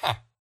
villager
yes3.ogg